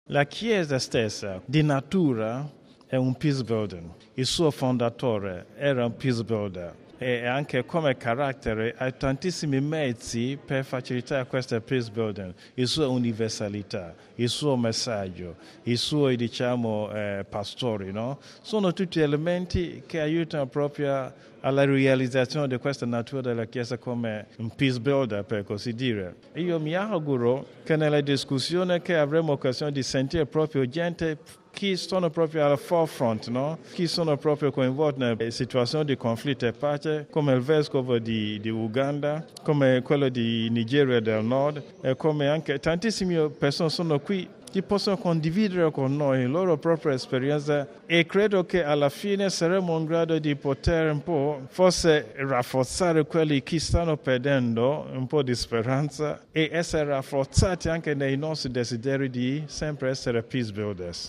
Radiogiornale del 29/05/2012 - Radio Vaticana